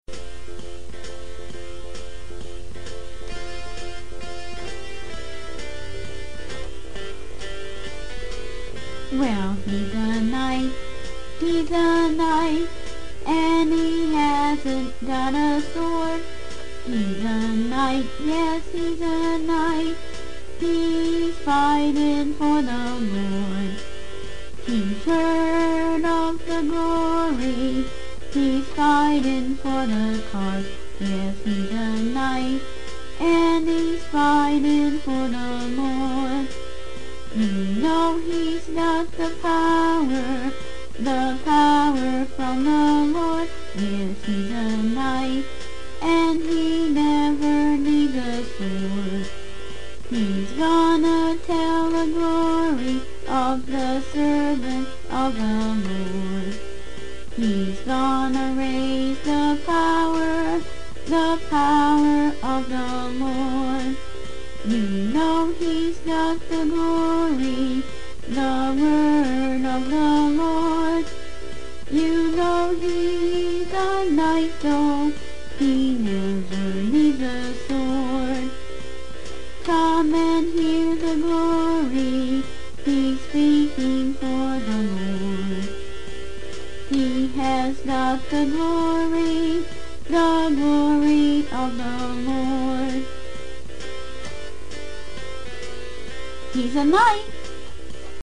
Sing This Song
And Accompany Herself On Her Keyboard.